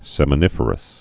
(sĕmə-nĭfər-əs)